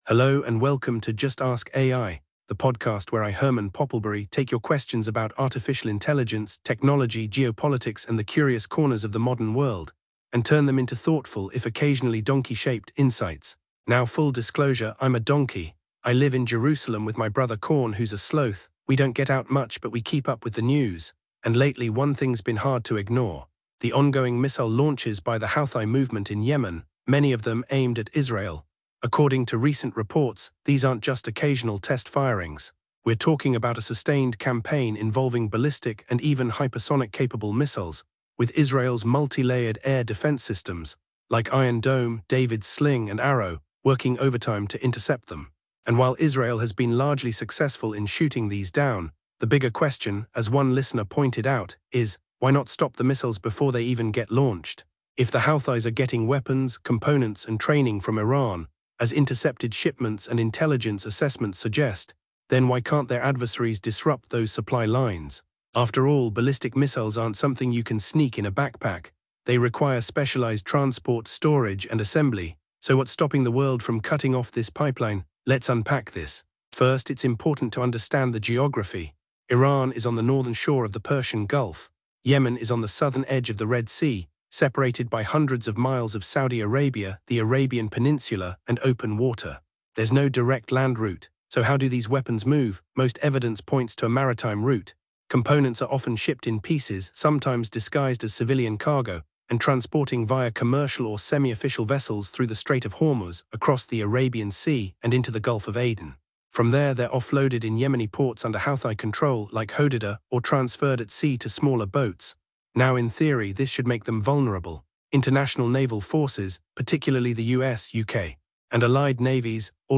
AI-Generated Content: This podcast is created using AI personas. Please verify any important information independently.